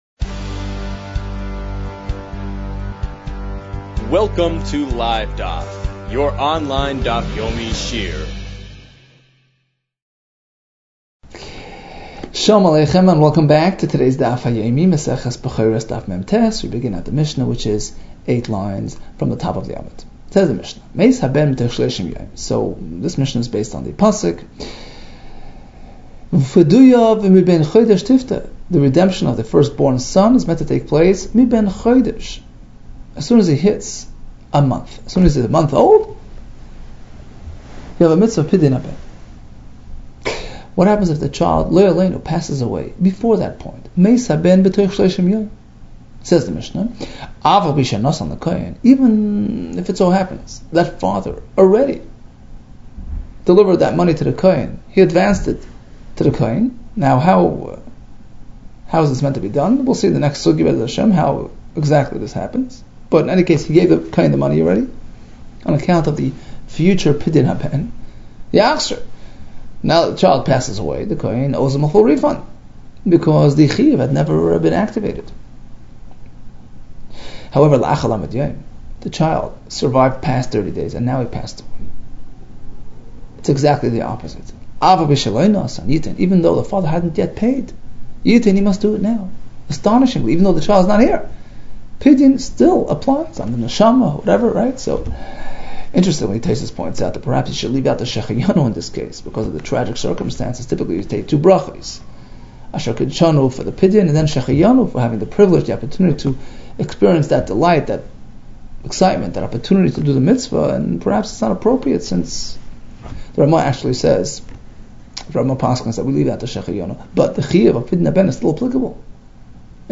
Bechoros 48 - בכורות מח | Daf Yomi Online Shiur | Livedaf